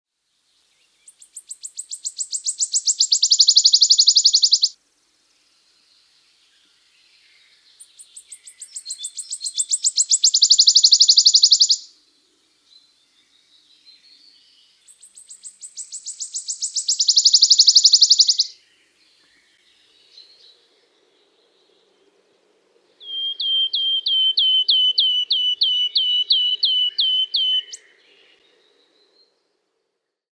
Kuuntele: Sirittäjän laulu kiihtyy loppua kohden
Sirittäjää on kutsuttu kolikkolinnuksi, sillä sen laulu kiihtyy loppua kohden kuin pöydällä kierivä kolikko. “Tsip … tsip … tsip, tsip, tsip, tsi-tsi-tsi-tsitsitsitsivyyyrrrrrrrrr” Tämän lisäksi usein kuuluu surumielinen, nopeahko pehmeä viheltely tjyyh tjyyh tjyyh-tjyyh-tjyyh.